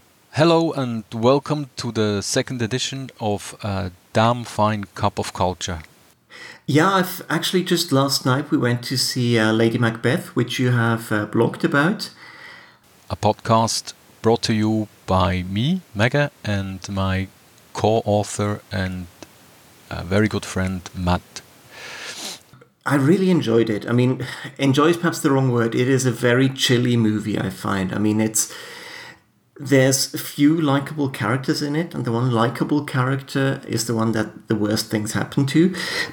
Both of your clips have “rumble” or low pitch tones that aren’t useful.
Chris’s Compressor to more or less even out the volume changes and then Limiter to take the peaks down a bit This is a badly cut “podcast” where I cut between the two tracks.